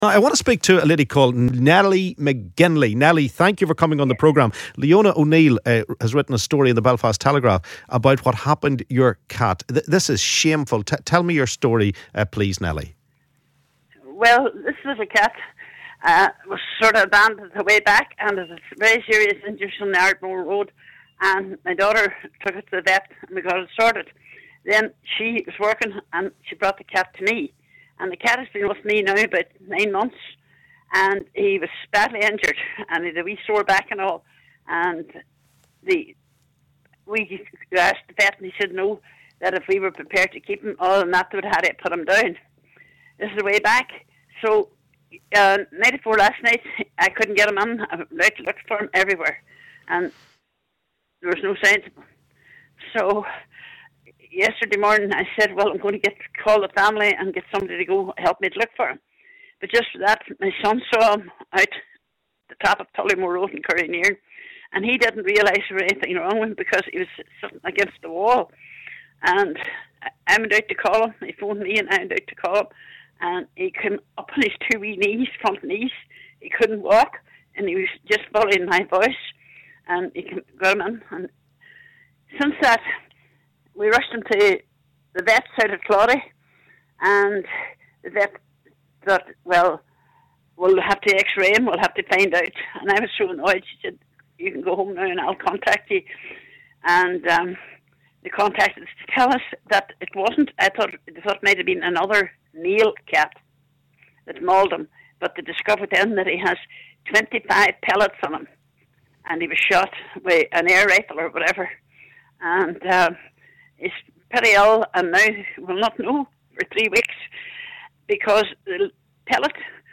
LISTEN ¦ Derry cat owner speaks out after beloved pet is left blind in one eye and fighting for life after being shot with pellet gun